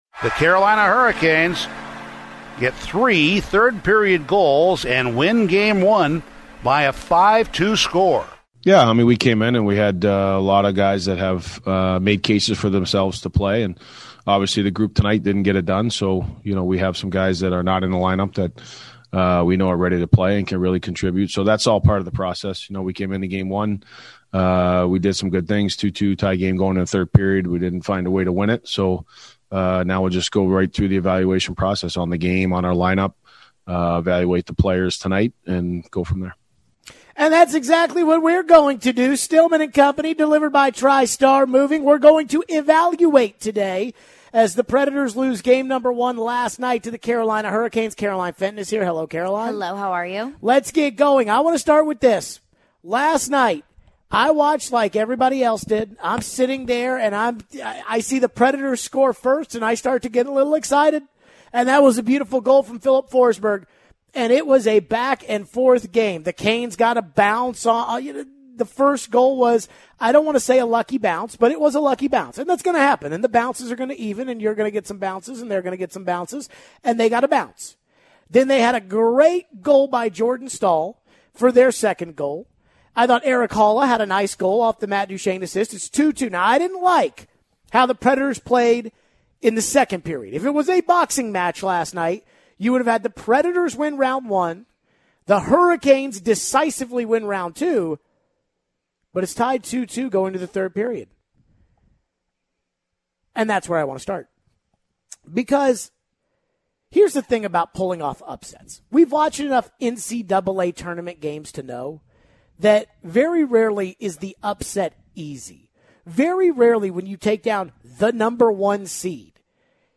What about the lineup decisions and do we think it cost them the game? We take your calls and texts.